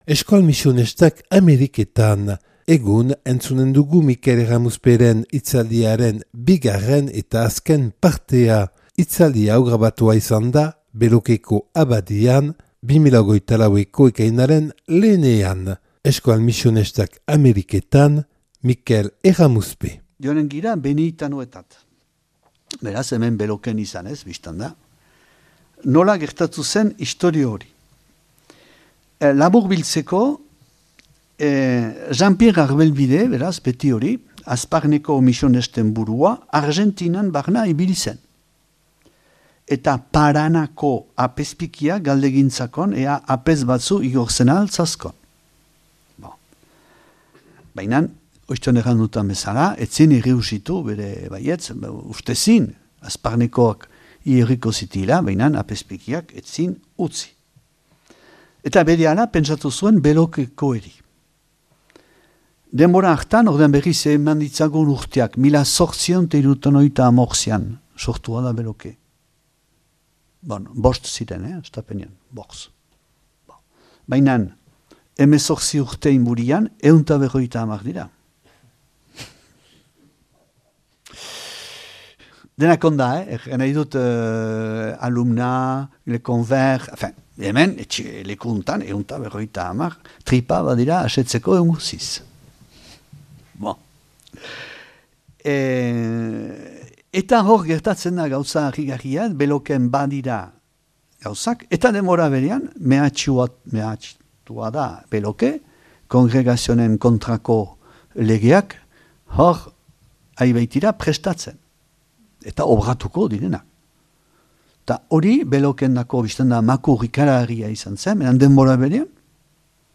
(Belokeko Abadian grabatua 2024. ekainaren 1ean)